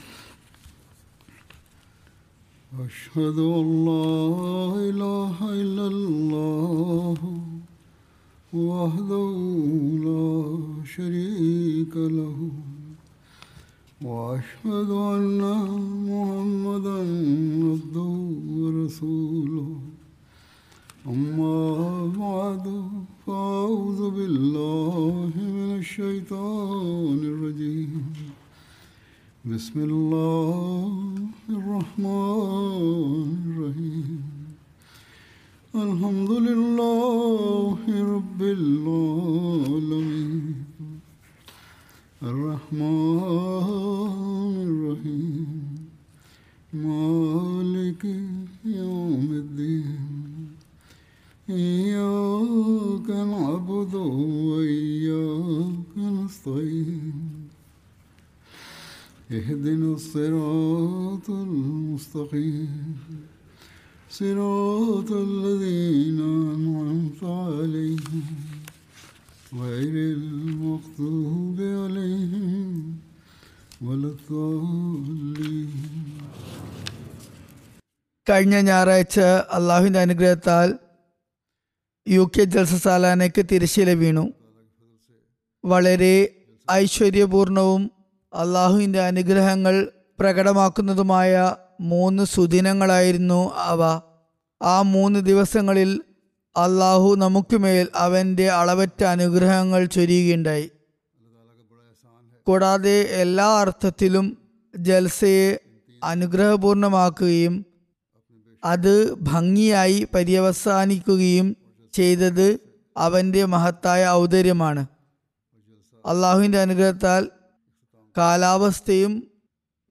Malayalam Translation of Friday Sermon delivered by Khalifatul Masih